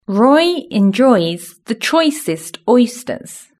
Index of /platform/shared/global-exercises/pron-tool/british-english/sound/sentences